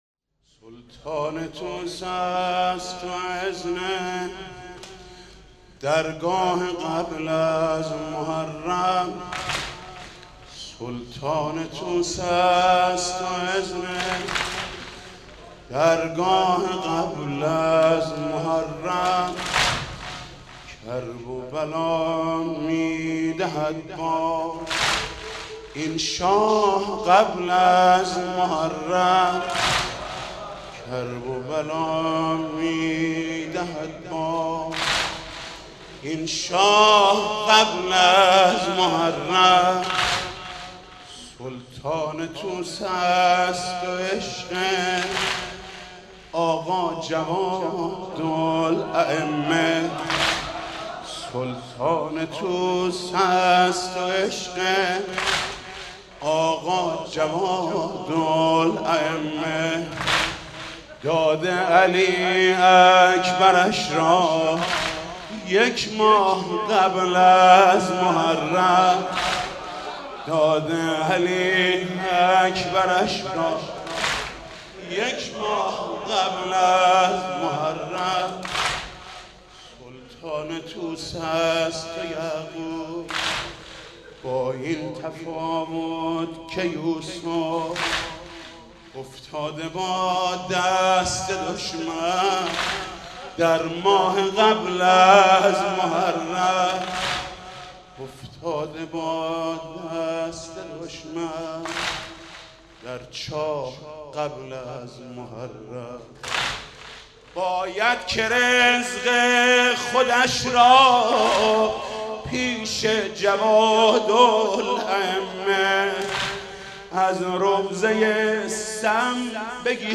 «شهادت امام جواد 1393» واحد: سلطان طوس است و درگاه قبل از محرم